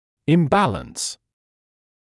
[ɪm’bæləns][им’бэлэнс]дисбаланс, диспропорция; несоответствие